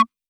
Index of /musicradar/retro-drum-machine-samples/Drums Hits/WEM Copicat
RDM_Copicat_SY1-Perc03.wav